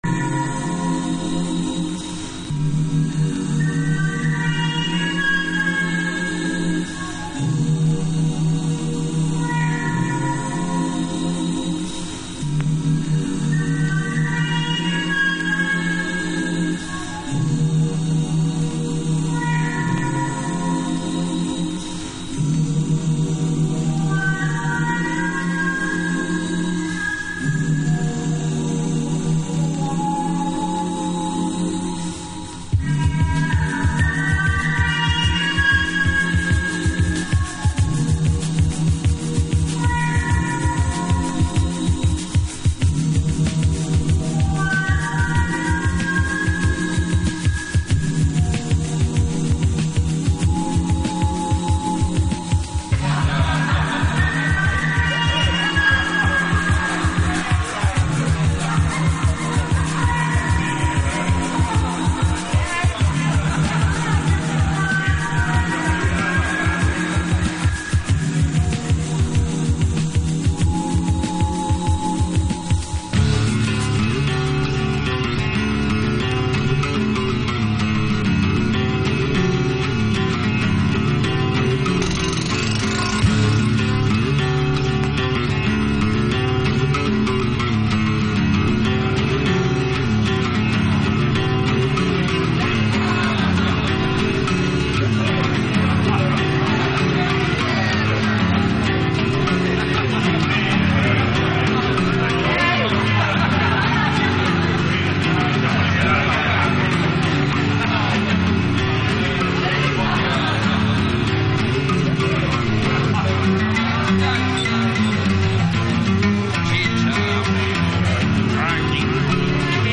60'sサイケや昨今のサイケデリック音響のレイドバックビートをお探しの方にも聴いていただきたいサウンド。